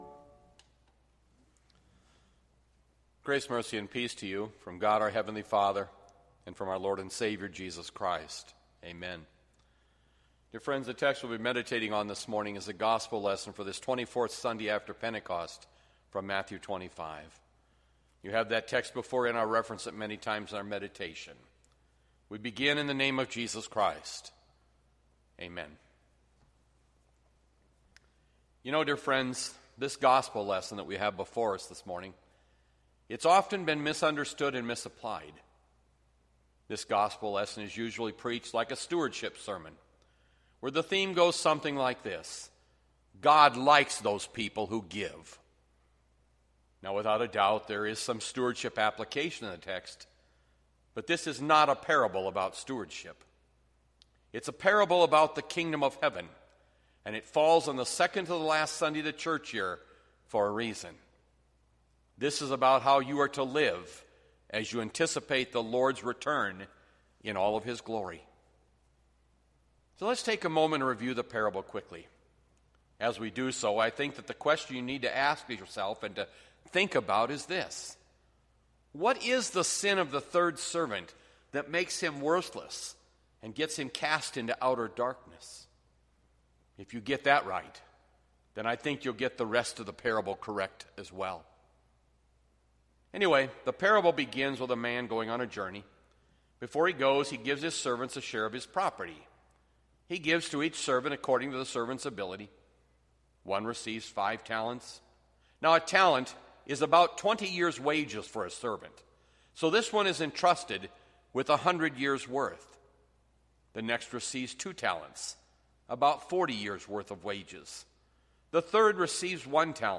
Bethlehem Lutheran Church, Mason City, Iowa - Sermon Archive Nov 15, 2020